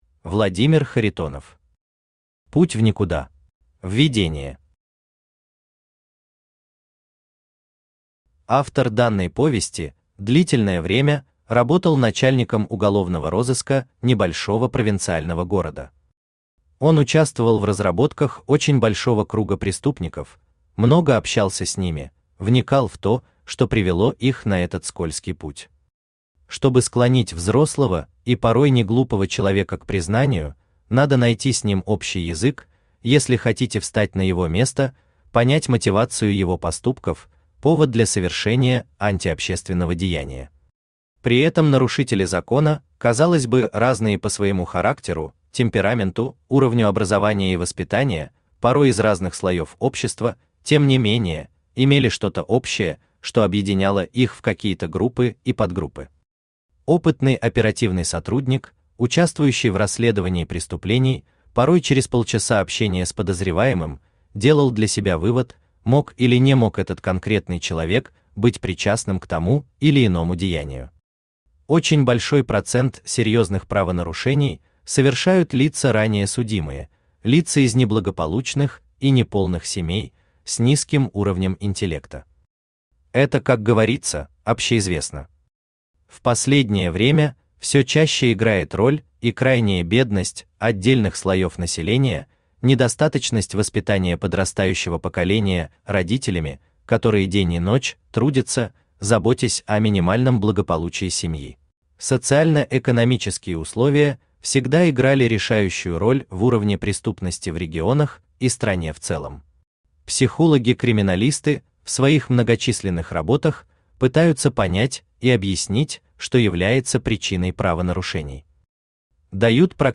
Аудиокнига Путь в никуда…
Автор Владимир Юрьевич Харитонов Читает аудиокнигу Авточтец ЛитРес.